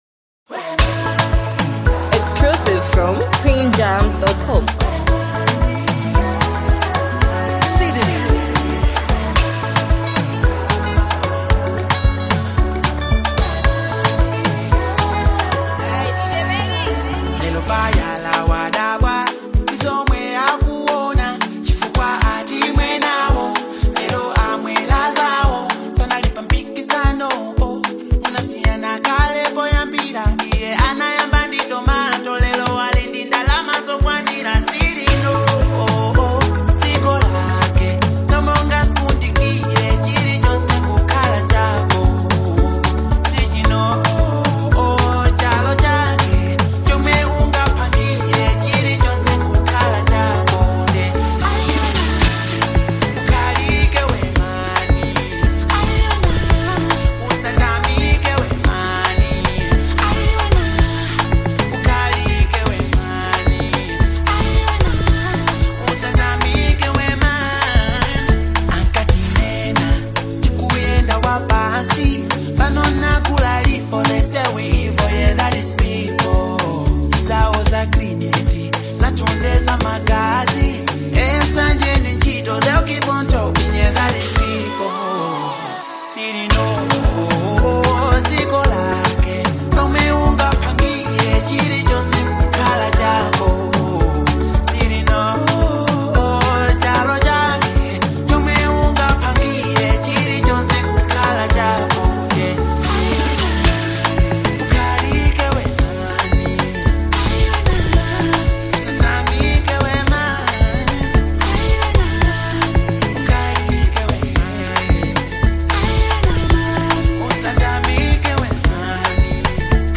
heartfelt and melodic record